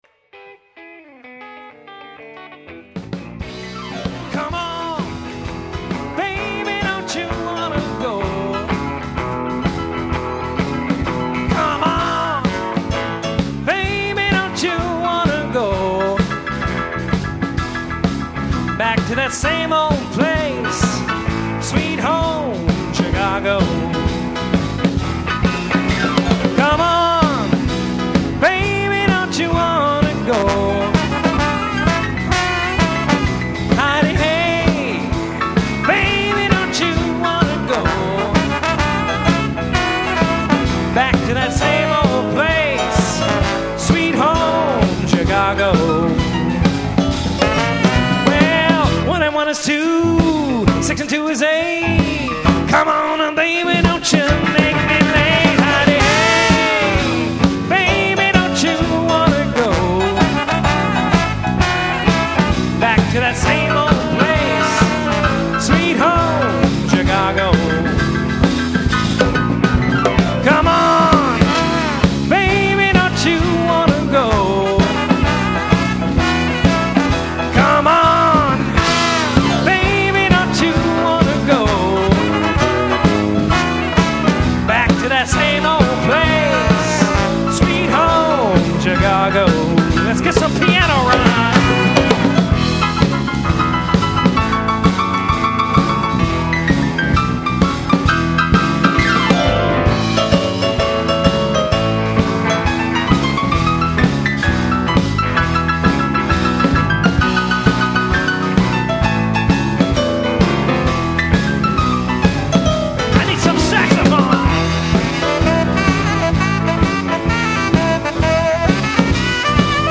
from live performances